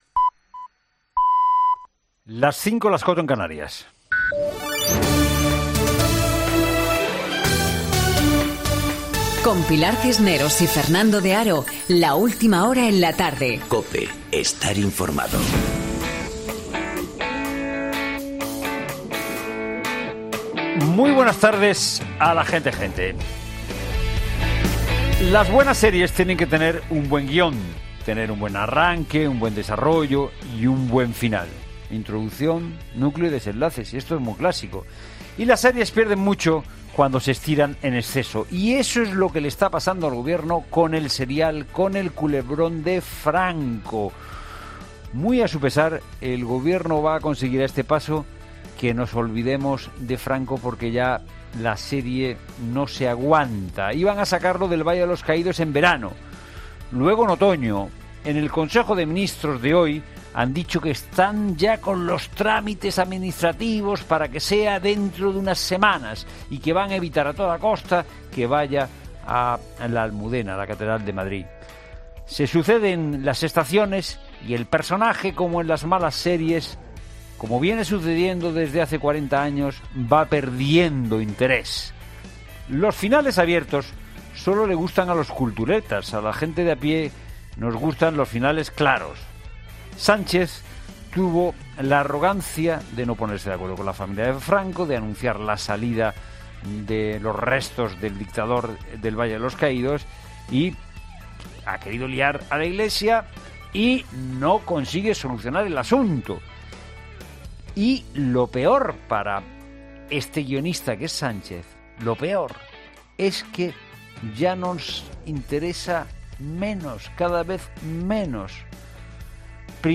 Boletín de noticias COPE del 8 de noviembre de 2018 a las 17.00 horas